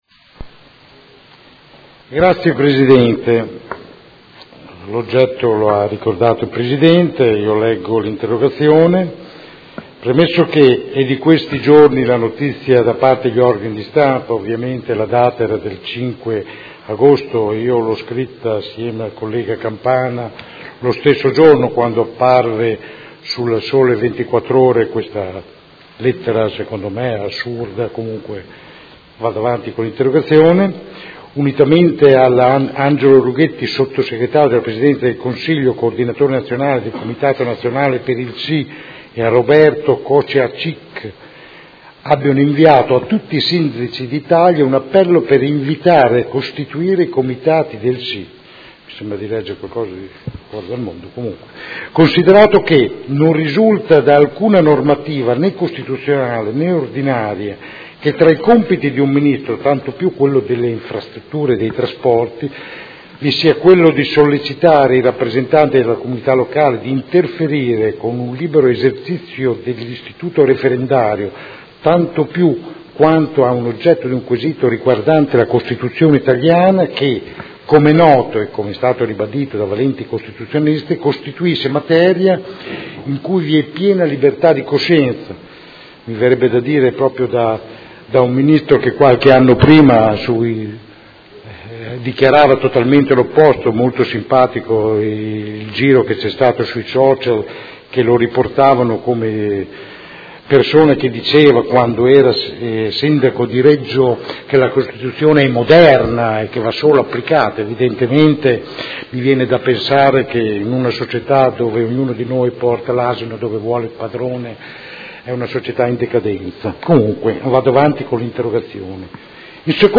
Seduta del 22/09/2016 Interrogazione del Consigliere Rocco (FAS-SI) e del Consigliere Campana (PerMeModena) avente per oggetto: Lettera del Ministro delle Infrastrutture Del Rio